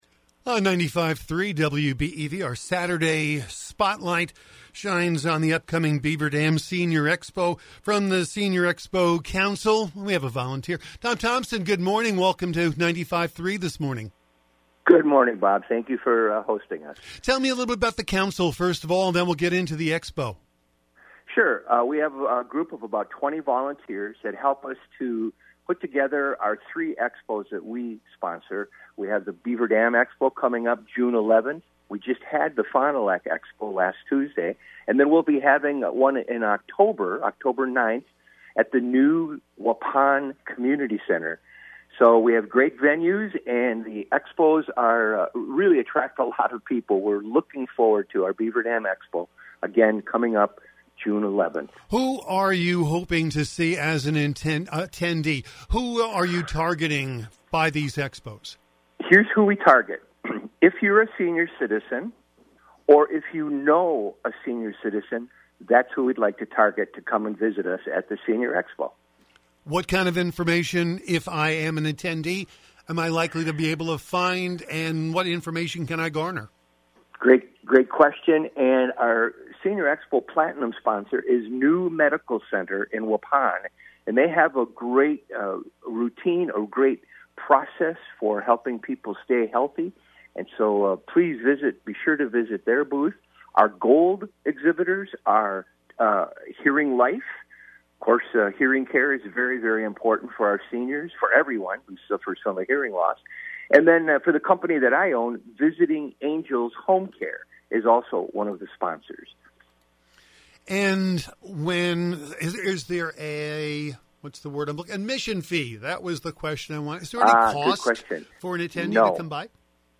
Saturday Spotlight Interview
WBEV-Interview.mp3